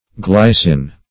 Glycin \Gly"cin\, n. [Gr. glyky`s sweet.]